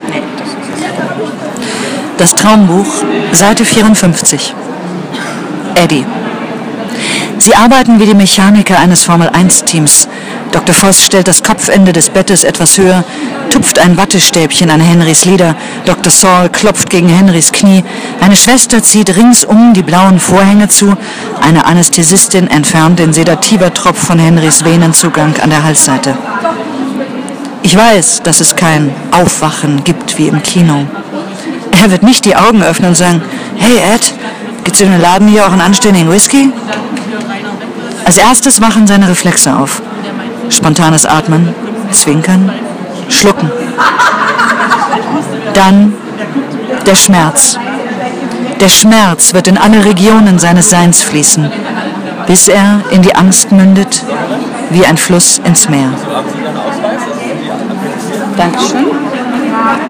Lesung_NinaGeorge.m4a